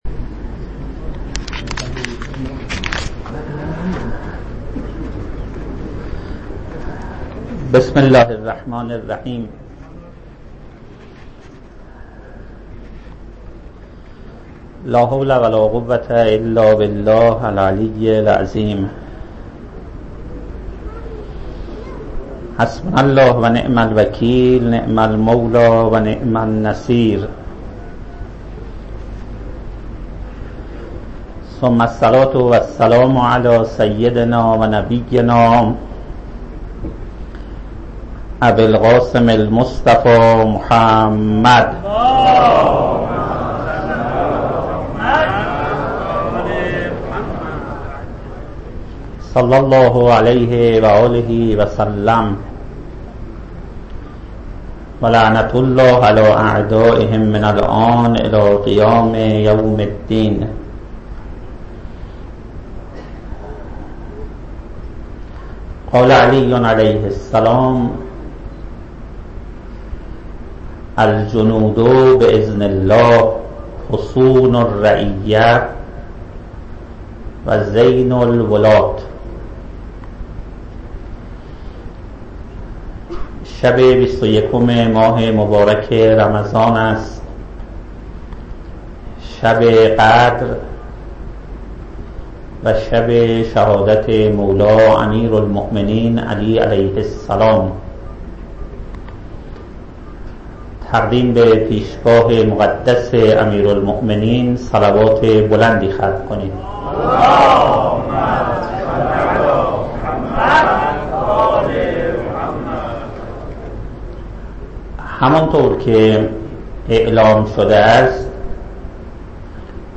چکیده سخنرانی شب شهادت امیرالمومنین(ع) سال ۹۷ + فایل صوتی + گزارش شفقنا